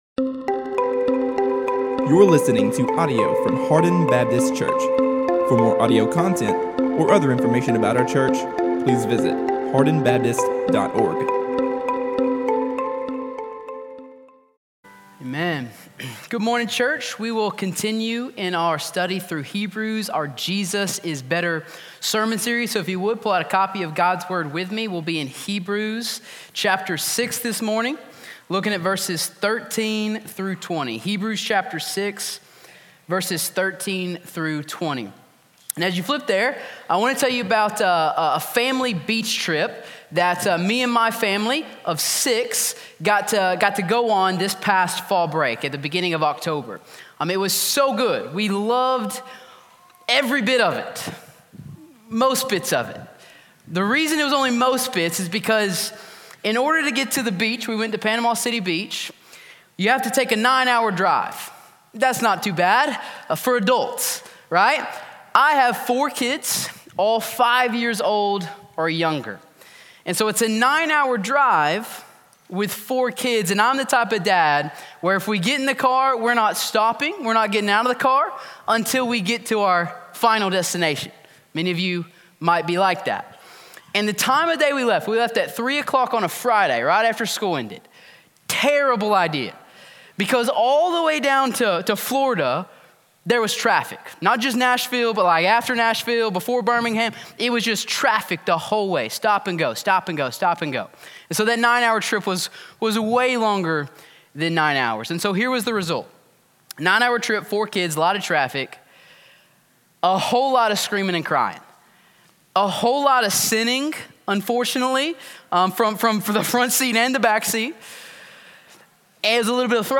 All Sermons – Hardin Baptist Church